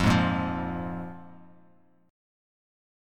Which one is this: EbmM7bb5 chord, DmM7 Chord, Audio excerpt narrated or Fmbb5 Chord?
Fmbb5 Chord